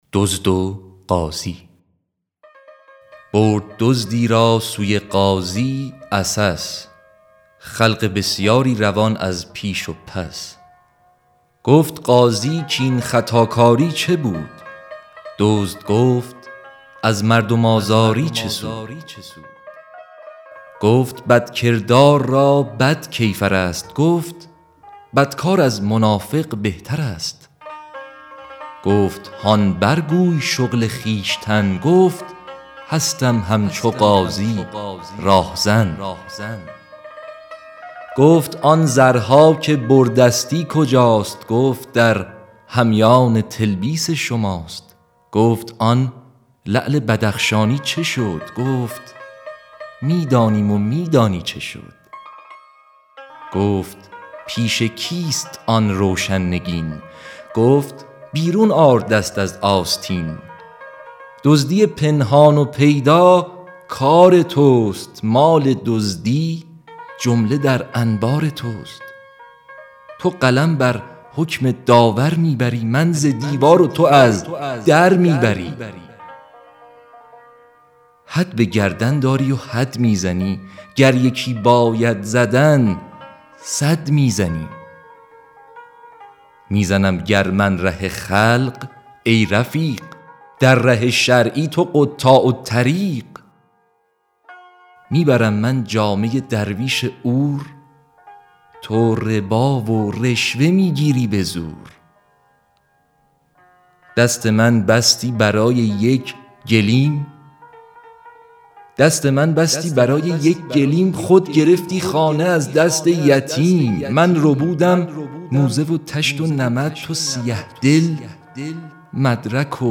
اطلاعات دکلمه